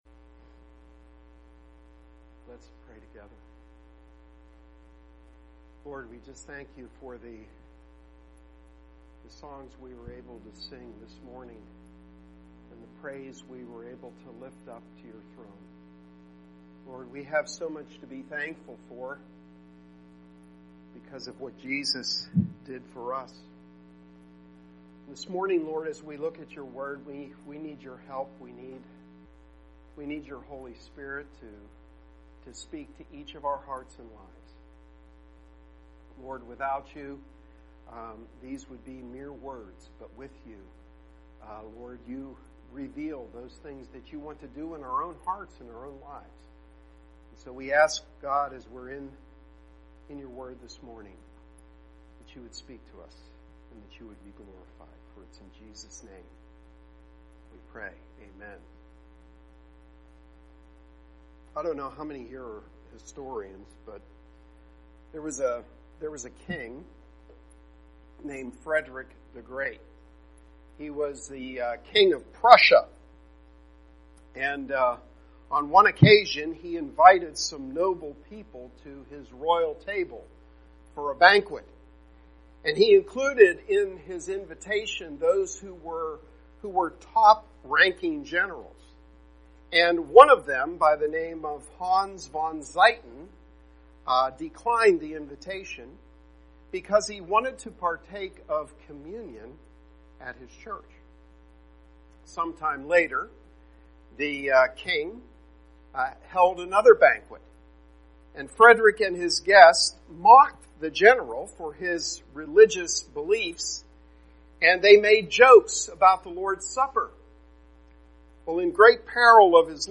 Sermon-8-5-18v2.mp3